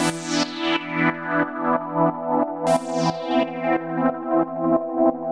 GnS_Pad-alesis1:8_90-E.wav